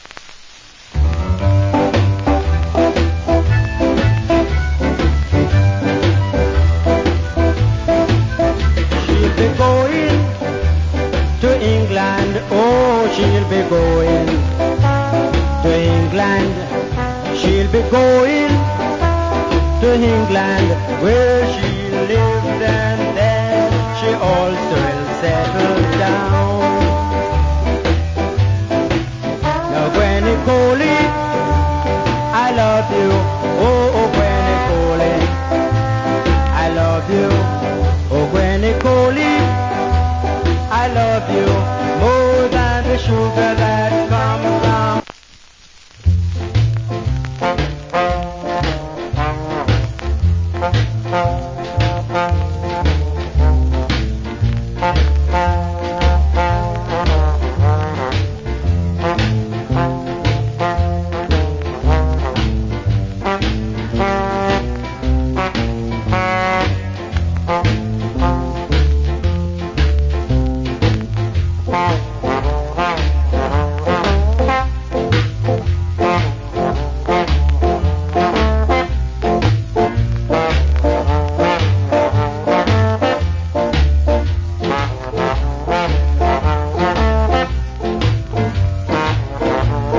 Good Jamaican R&B Vocal.